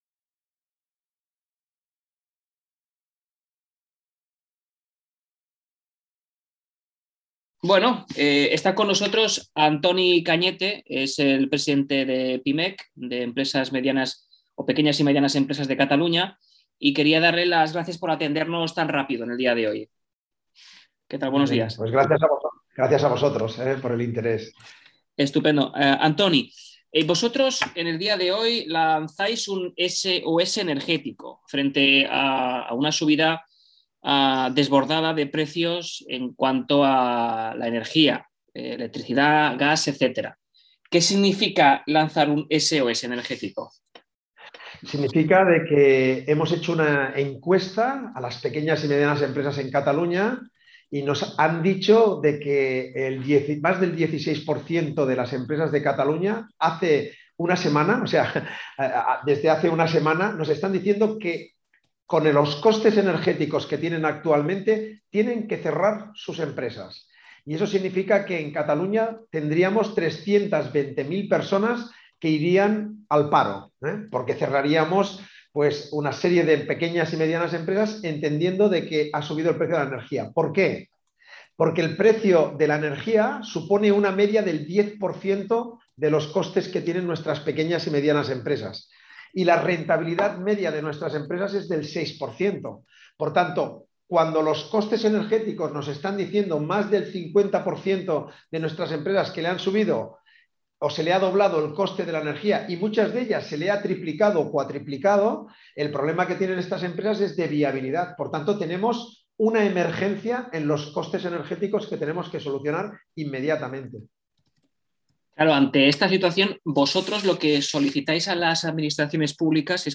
Escuchar aquí la entrevista: sos-energecc81tico.mp3 Hola y bienvenidos a Historia en Primera Persona.